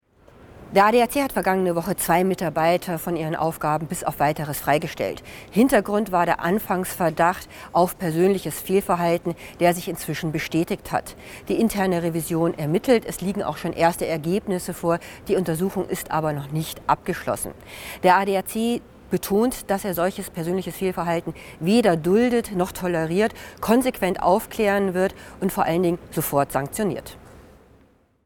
ADAC Stellungnahme zu Freistellung Mitarbeiter